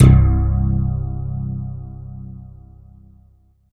47 BASS 80-R.wav